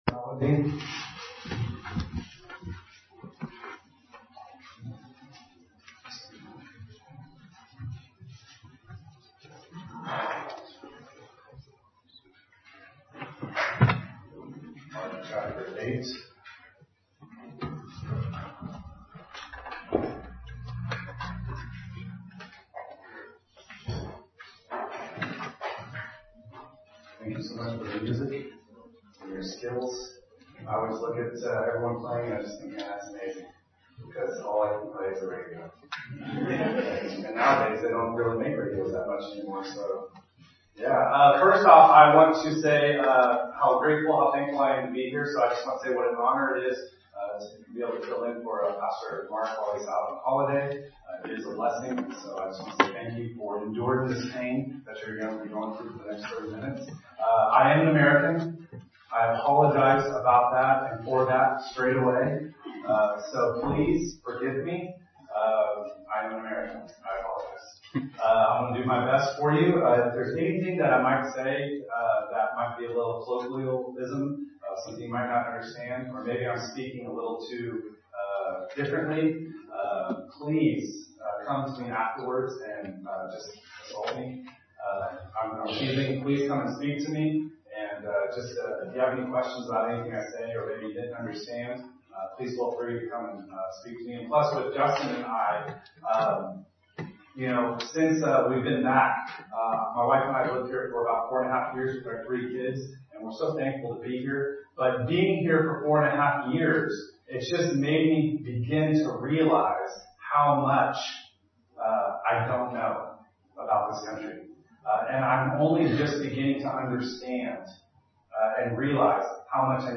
All sermons preached at Crockenhill Baptist Church